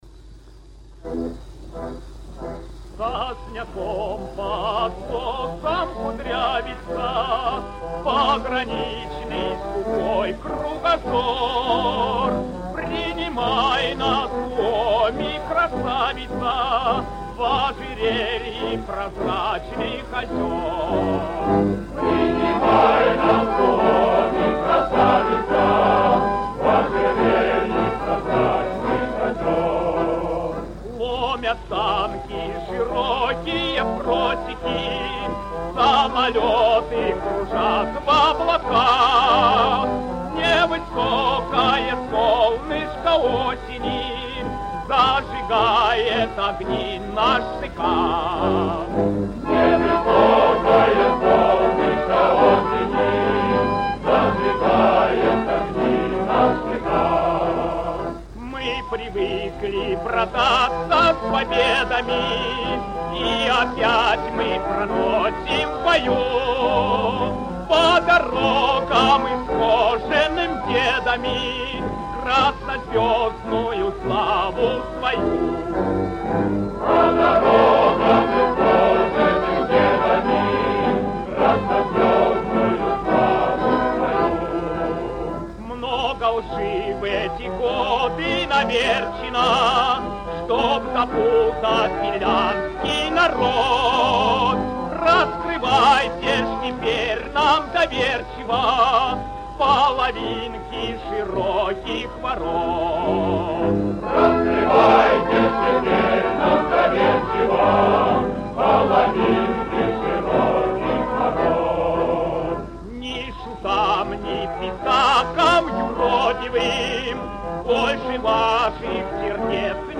Грампластинка